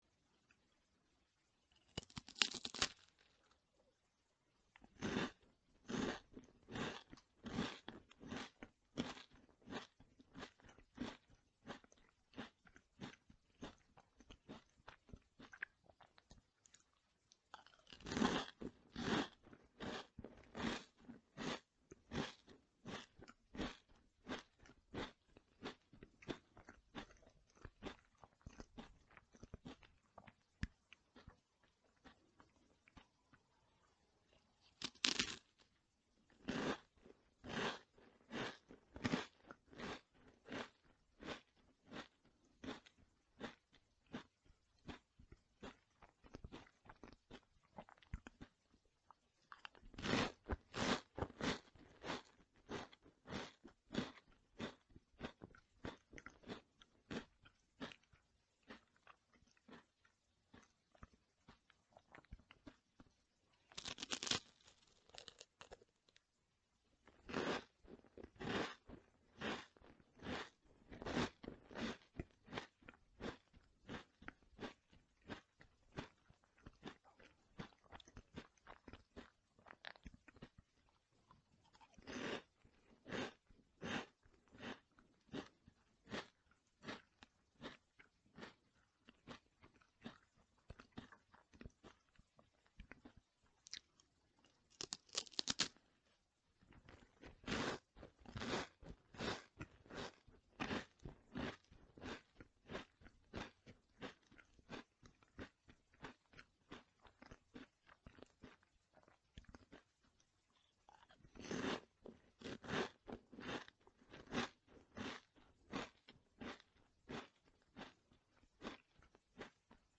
おすすめアンドロイドASMR【たべられるお菓子の音】ルマン●